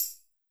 GAR Tamb.wav